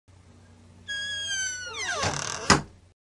puerta-cerrandose-1-Efecto-de-sonido.mp3
KFsq754YmlC_puerta-cerrandose-1-Efecto-de-sonido.mp3